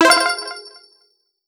item_collect_01.wav